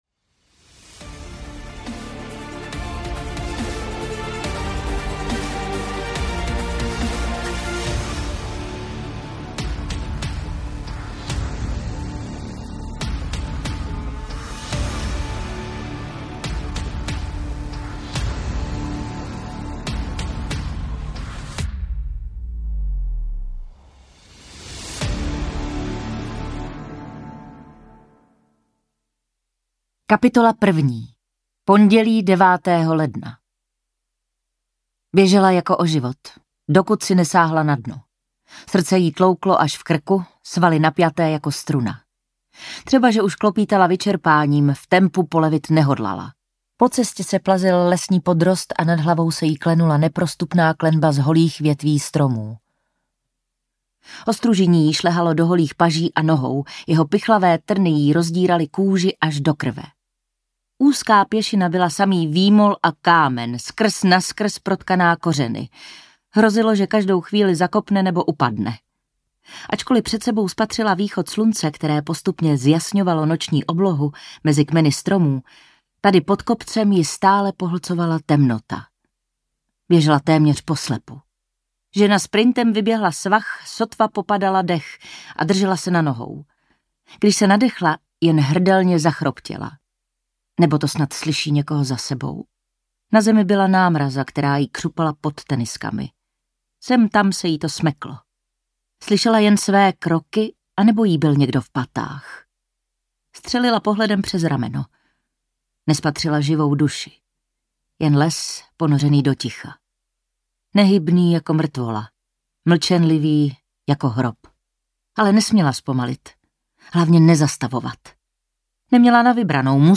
Dívky na kopci audiokniha
Ukázka z knihy